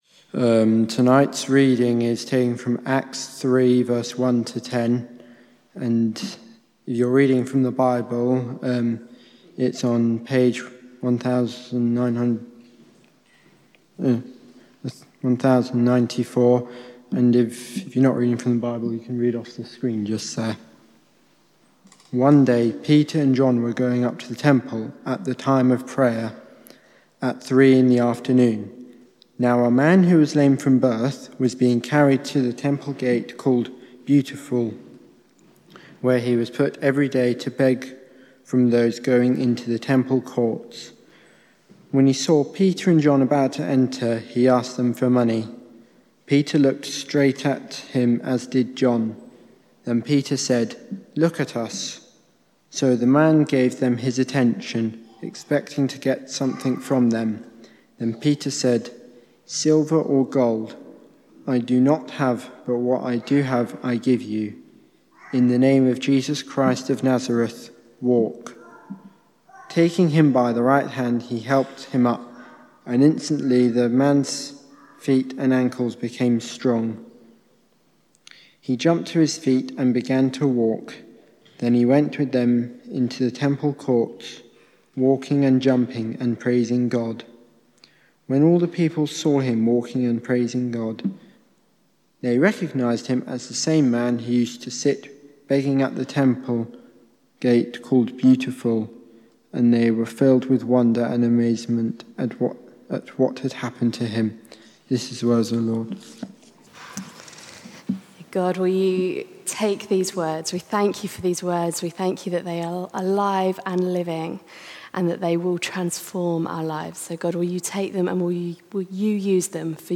From Service: "Guest Speakers"